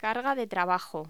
Locución: Carga de trabajo
voz